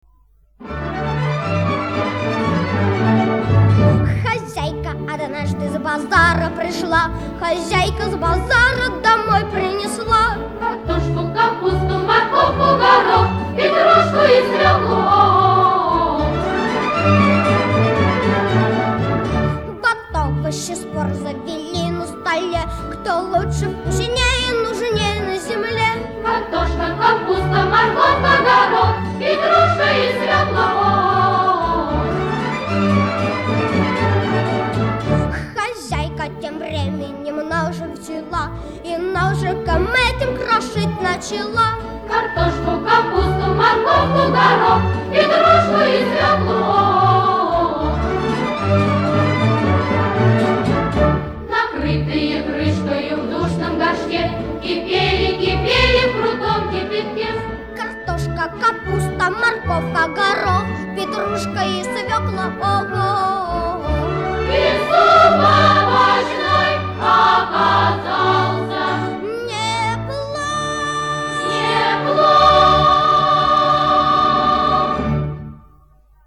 Исполняет: детский хор